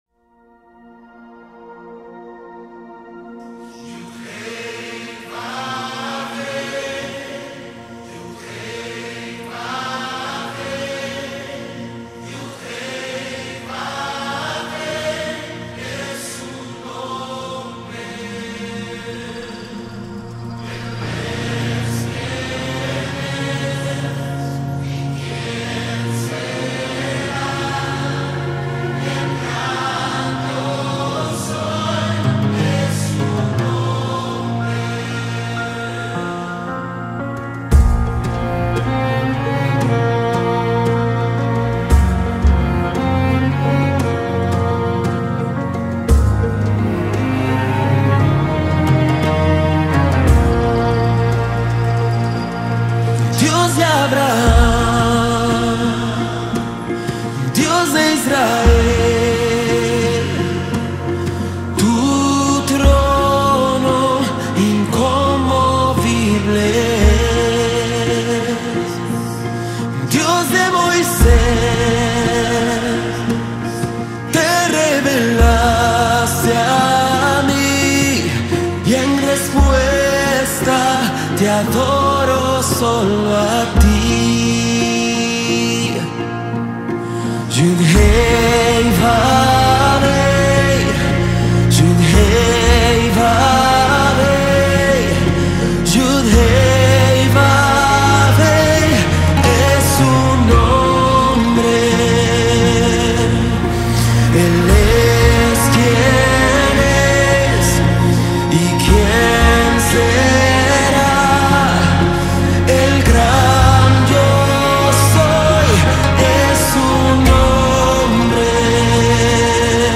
BPM: 86